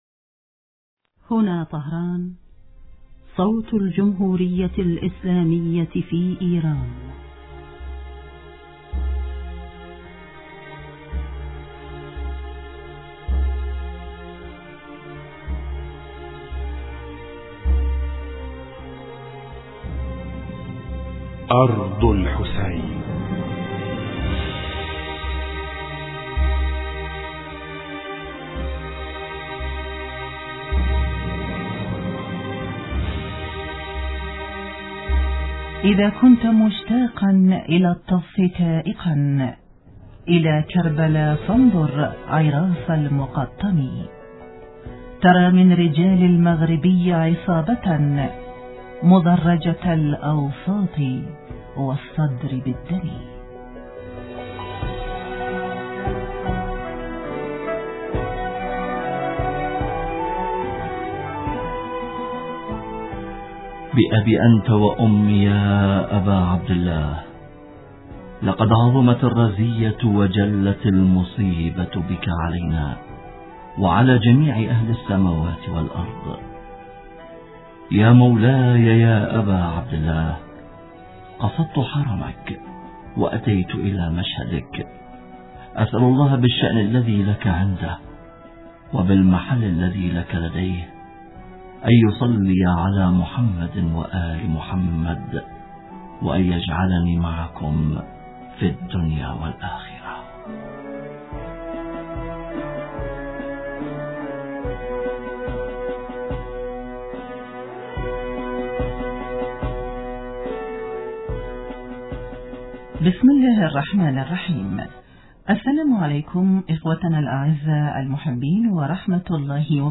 في الحديث الهاتفي التالي :